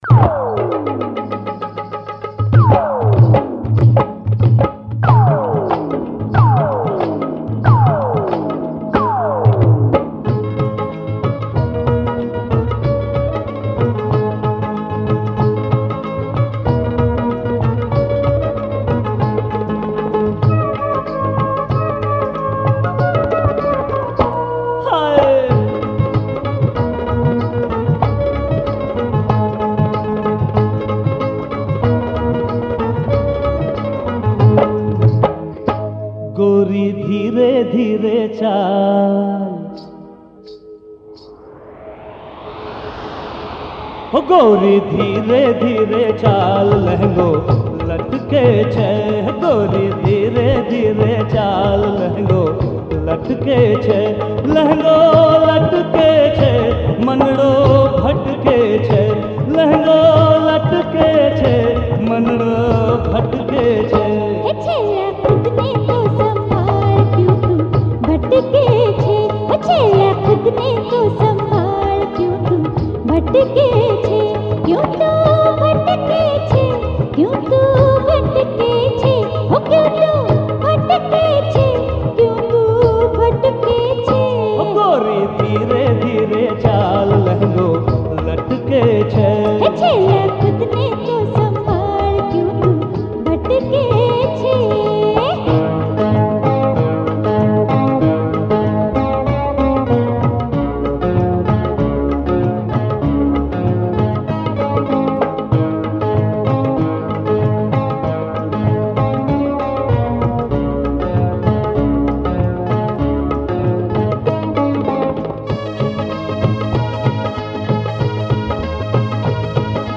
Rajasthani Folk Songs